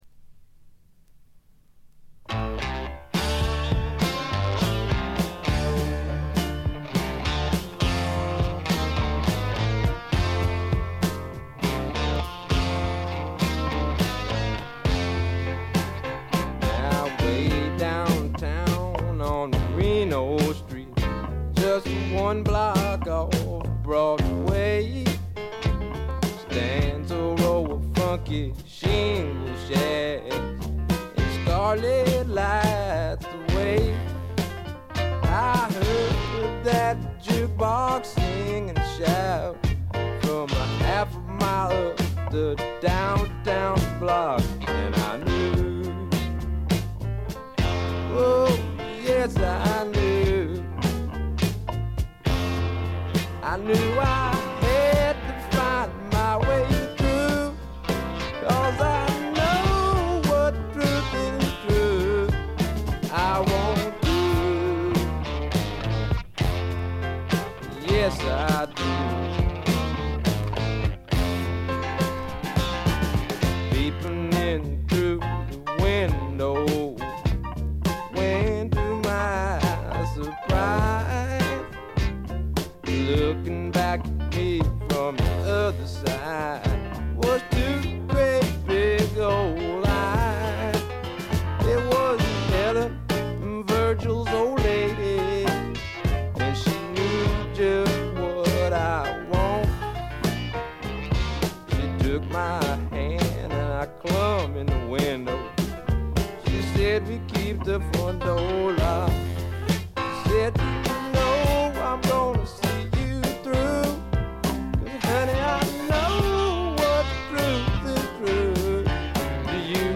ノイズ感無し。
まさしくスワンプロックの真骨頂。
それにしてもこのベースの音は尋常ではない凄みがあります。
試聴曲は現品からの取り込み音源です。
Vocal, Guitar, Keyboards